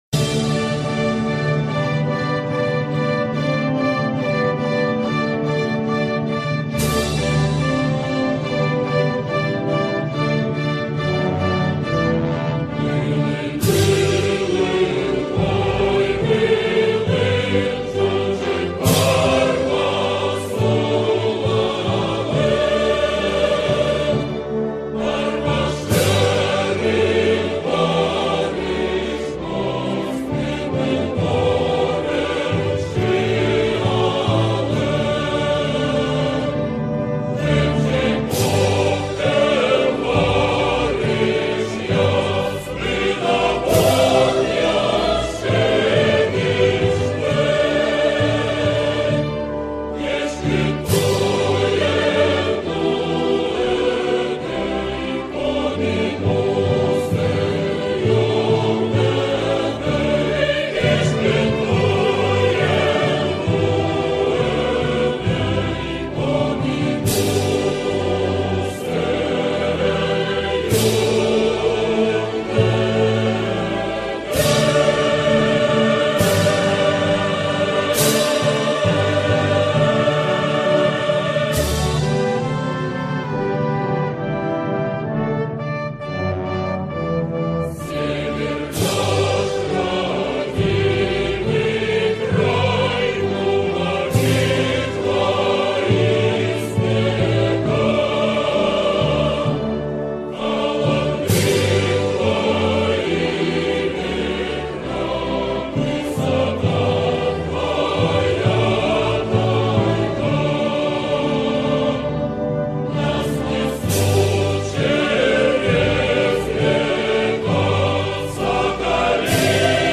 торжественное музыкальное произведение
со словами